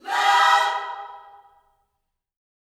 LOVECHORD3.wav